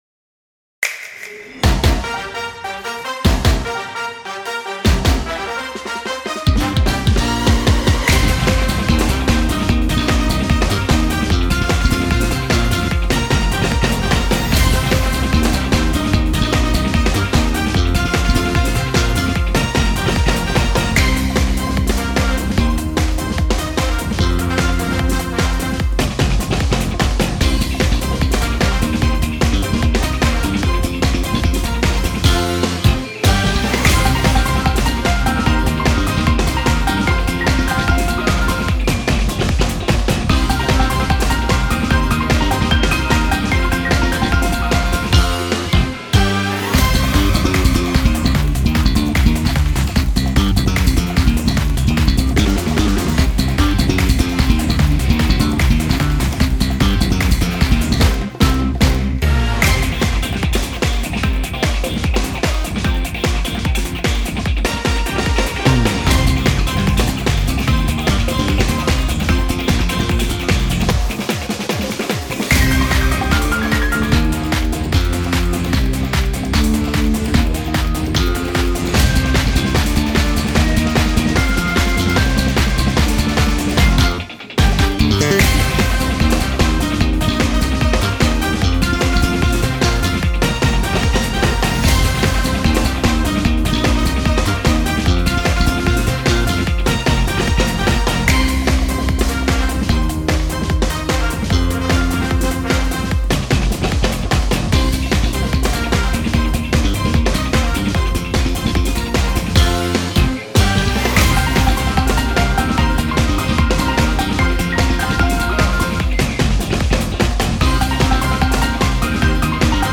ブラスが主体かと思いきやギターもベースもドラムもそれなりに主張してくるかっこいい系のBGMです。